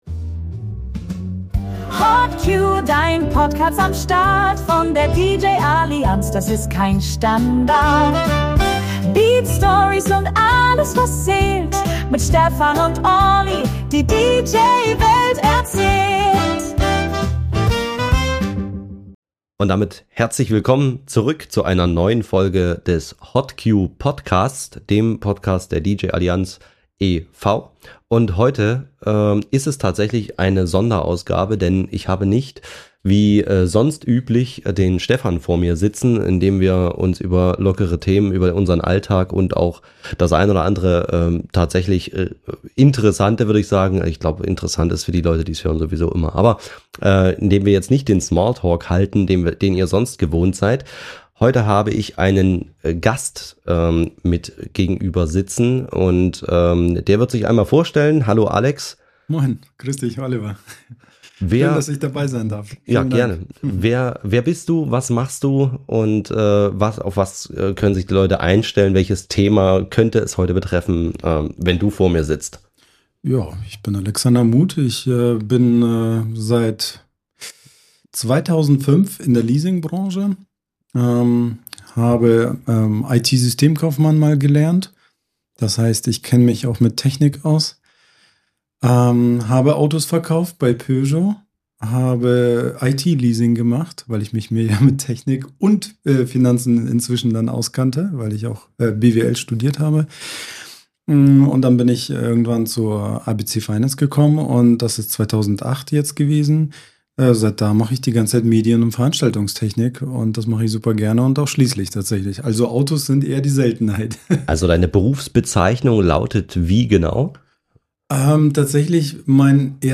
Gemeinsam gehen sie der Frage nach: Muss man als DJ wirklich alles besitzen – oder gibt es klügere Wege, sich Technik ins Business zu holen? Es geht um Leasing, Mietkauf, steuerliche Vorteile, Liquidität, absurde Versicherungsfälle (Spoiler: gestohlene Kameras am letzten Tag der Laufzeit!) und um die These, warum „alles bar zahlen“ ein veraltetes Mindset sein könnte. Ein tiefes, aber unterhaltsames Gespräch über Finanzen, Vertrauen und wie man sein DJ-Business strategisch auf stabile Füße stellt.